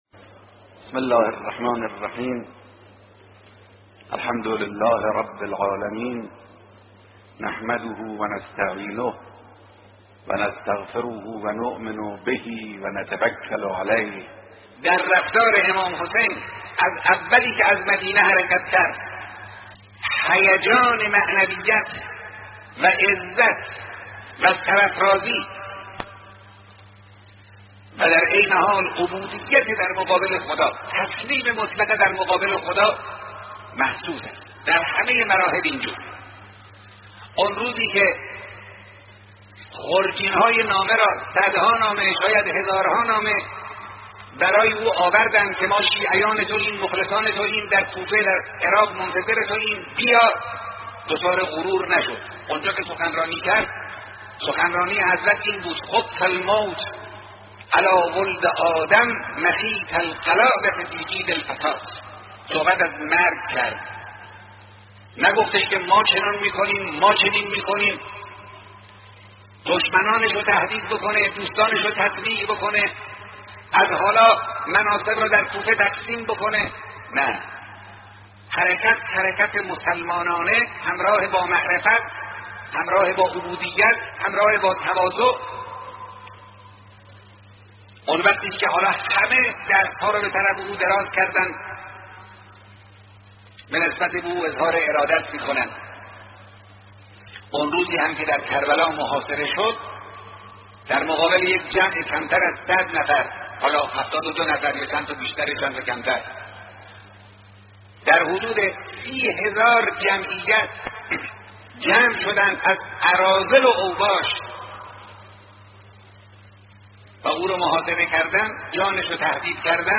این مجلس عزاداری اینترنتی، شامل دو بخش «منبر و عزاداری» است. در بخش «منبر»، سخنان رهبر معظم انقلاب درباره بصیرت عوام و خواص و نقش آن در وقوع حوادث سال شصت و یکم هجری قمری، عبرت‌ها و تحلیل واقعه‌ی عاشورا پخش می‌شود.